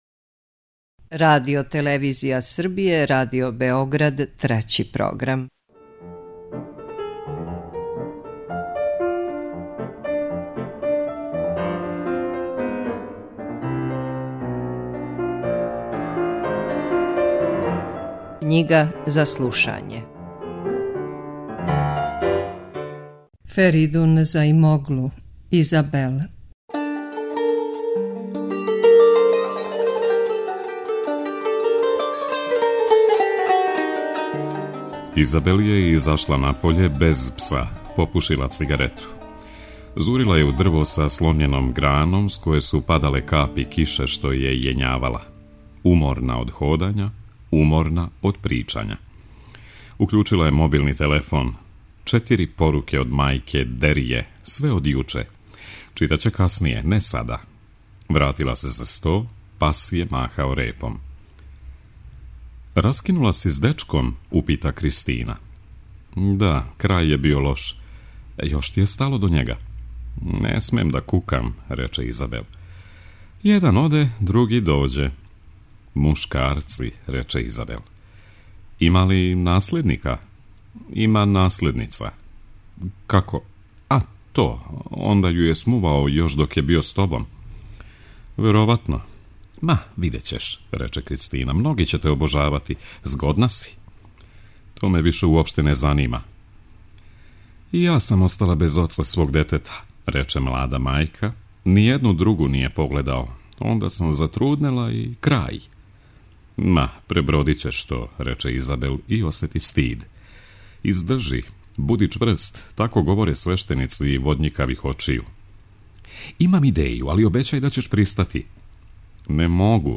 У емисији КЊИГА ЗА СЛУШАЊЕ током друге половине јула и прве половине августа на таласима Трећег програма читаћемо роман „Изабел”, чији је аутор Феридун Заимоглу, немачки писац турског порекла.